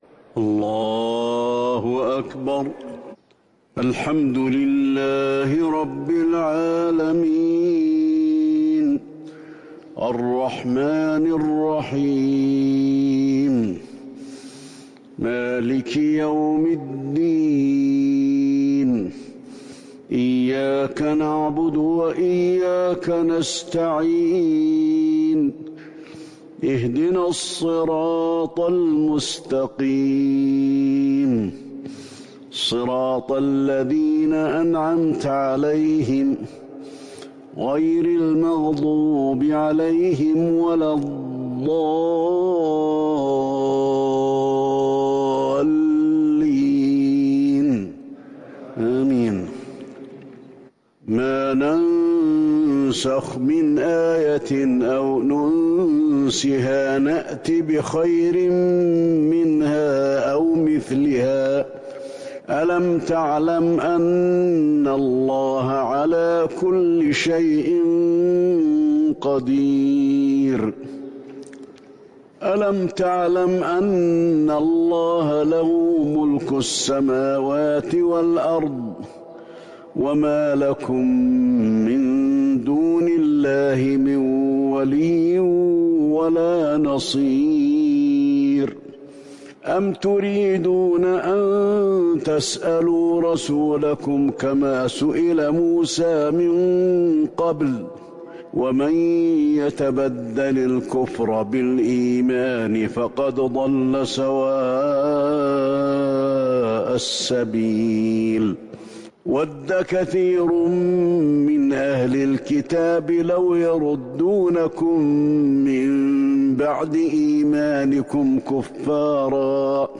تهجد ٣٠ رمضان ١٤٤١هـ من سورة البقرة ١٠٦-١٢٣ > تراويح الحرم النبوي عام 1441 🕌 > التراويح - تلاوات الحرمين